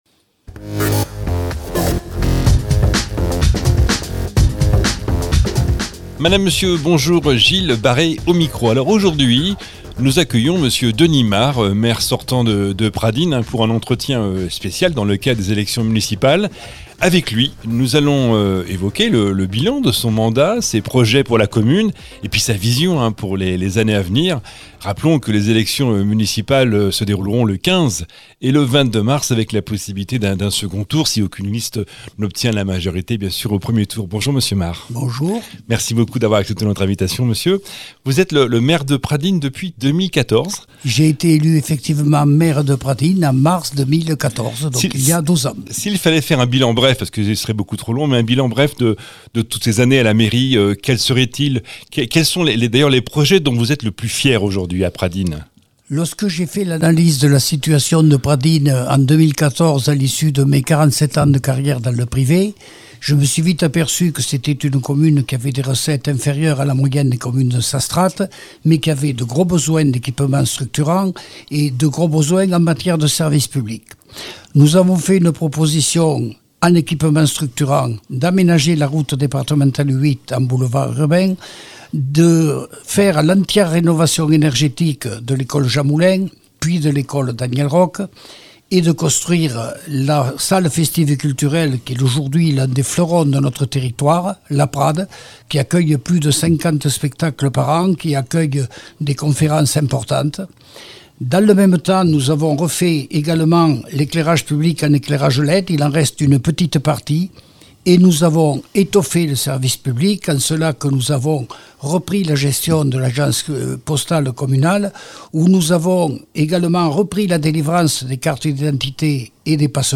Invité de notre antenne, il est revenu sur son bilan, ses motivations et les priorités qu’il entend défendre pour les années à venir.